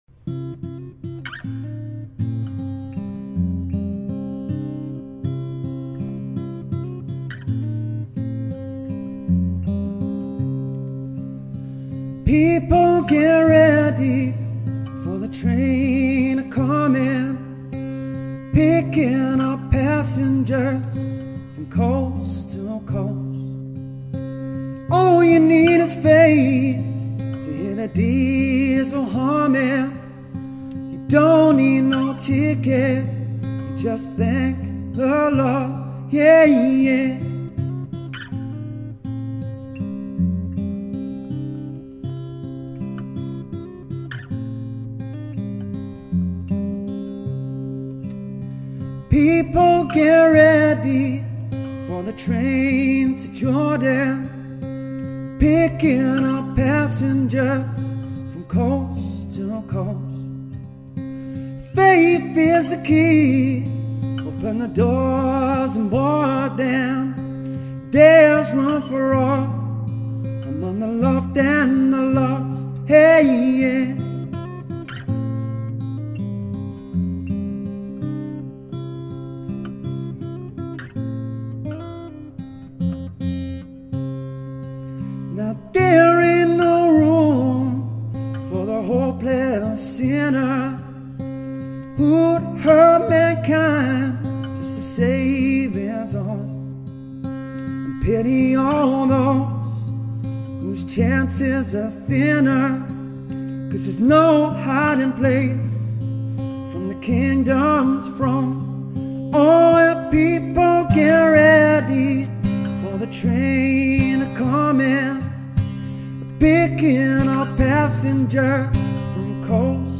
Acoustic Guitar Vocalist